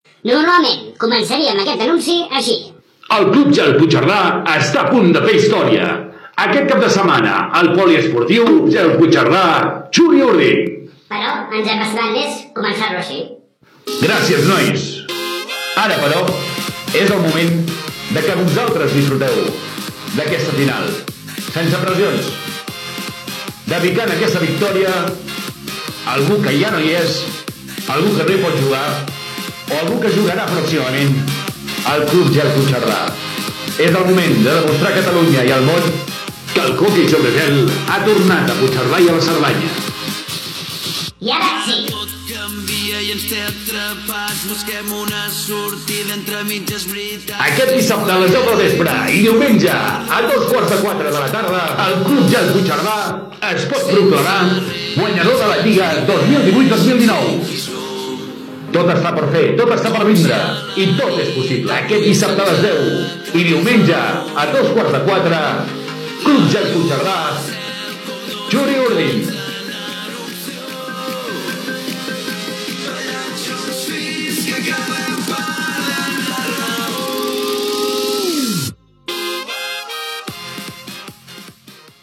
Anunci dels partits de tornada entre el Club Gel Puigcerdà i Txuri Urdin de la final de la Lliga 2018-2019 d'hoquei gel
FM